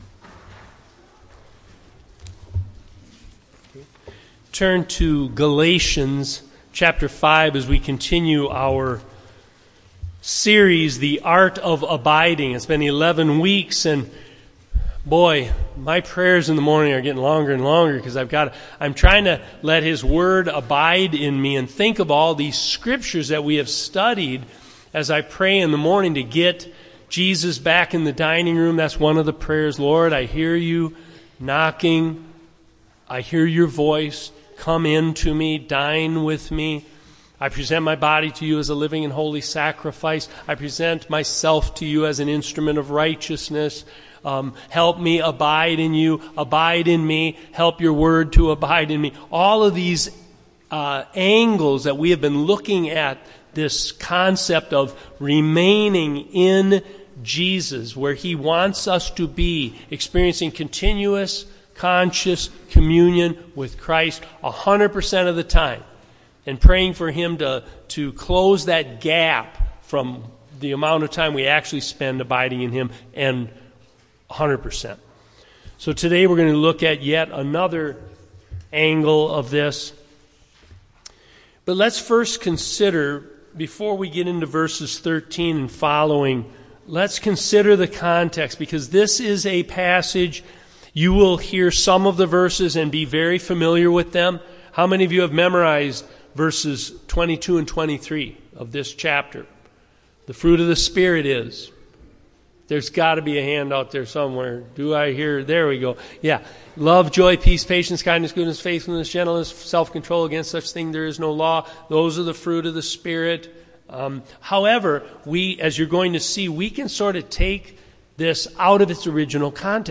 Sermon audio: 6-23-24